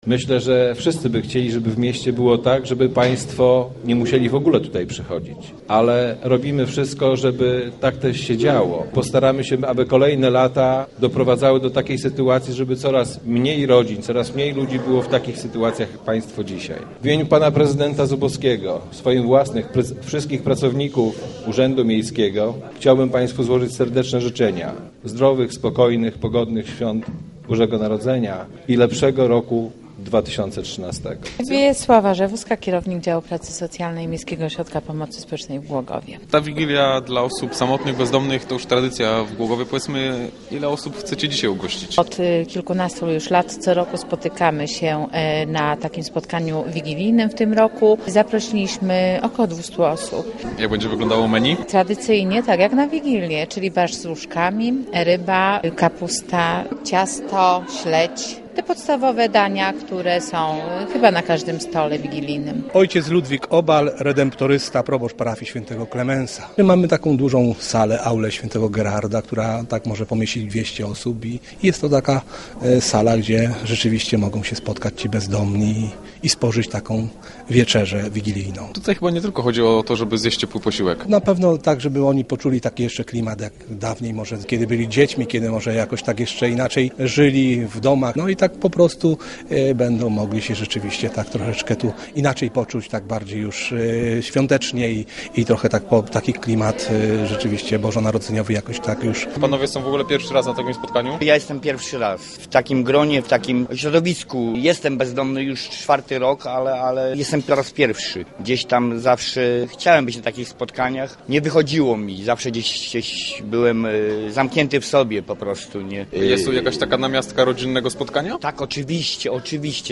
Osoby bezdomne, samotne i ubogie mogły spotkać się w piątek w Głogowie przy wigilijnym stole. Wieczerzę zorganizował Miejski Ośrodek Pomocy Społecznej oraz Caritas z parafii św. Klemensa.
Zanim wszyscy usiedli do stołów odśpiewali kolędę i podzielili się opłatkiem.